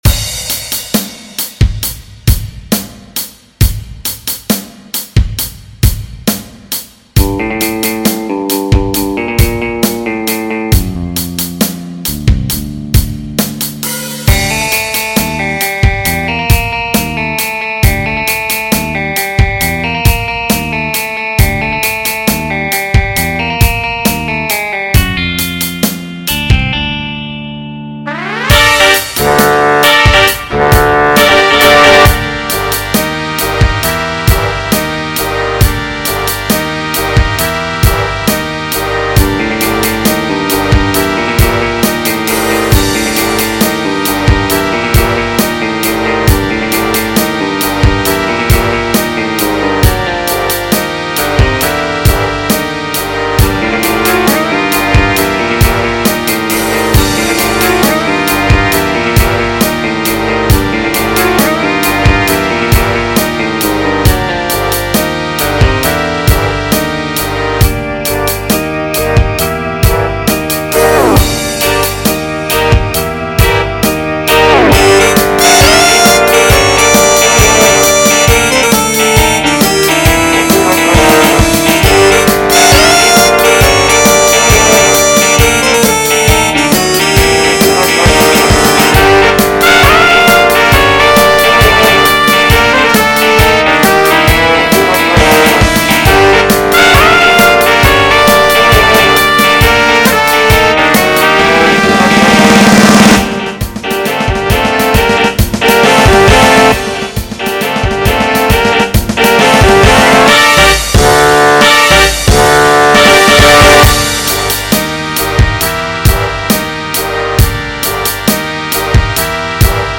• Beats Per Minute: = 135
• Key Signature: G Minor